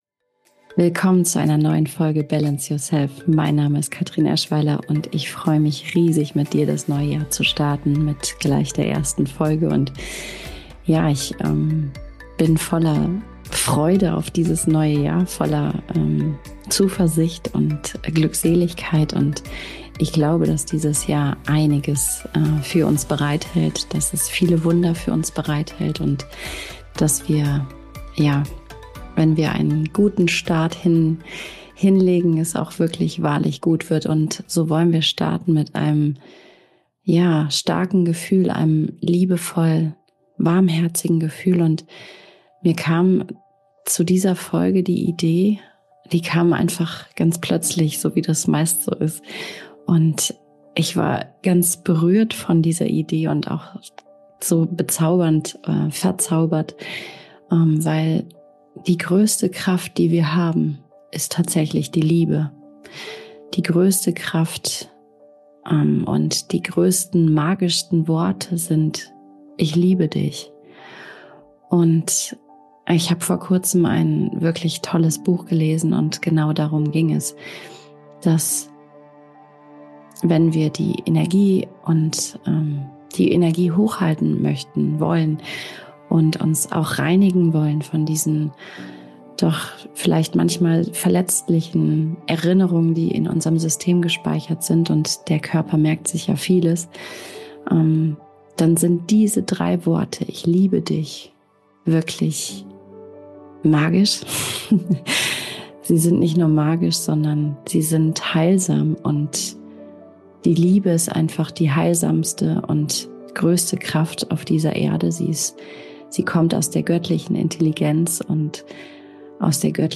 🎙 In dieser heutigen Folge kannst du wahrlich auftanken und in einer kleinen Meditation zu dir finden.